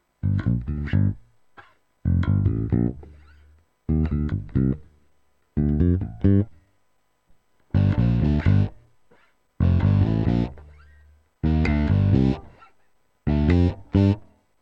So, meine Version des Fishman Fission Powerchord FX ist fertig.